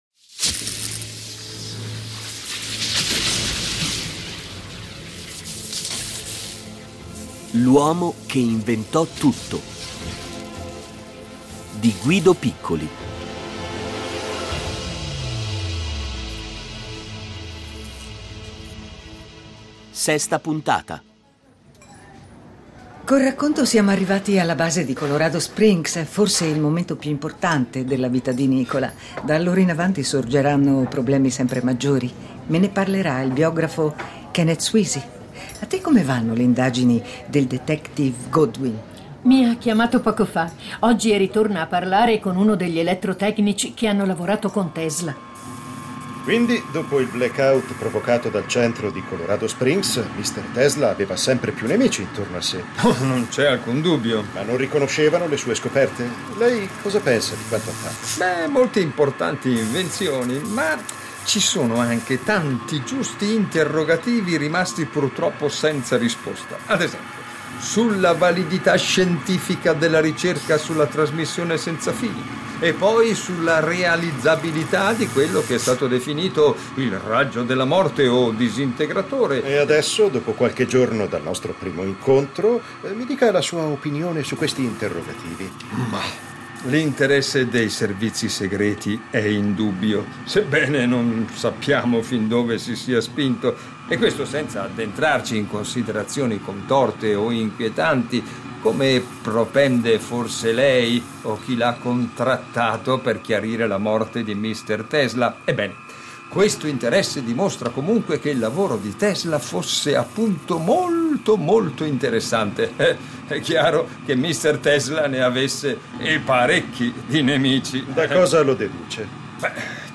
Originale radiofonico